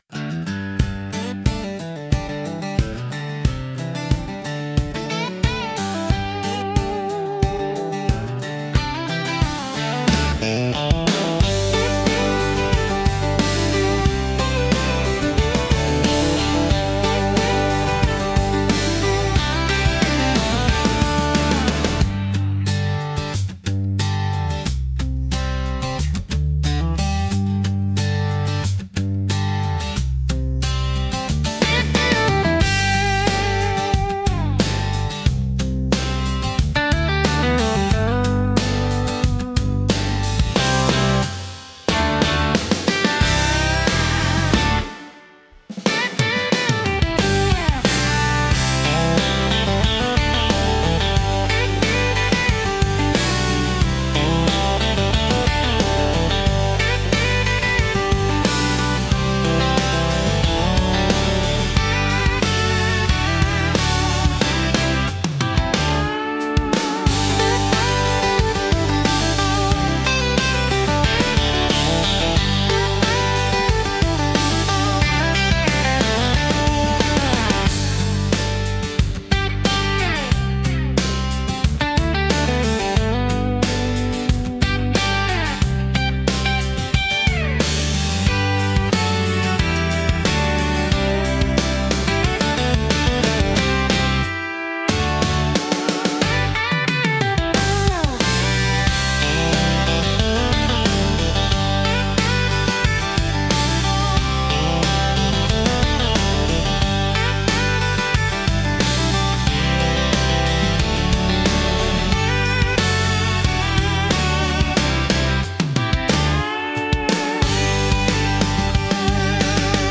聴く人に快適で穏やかな気持ちをもたらし、ナチュラルで親近感のある空気感を演出します。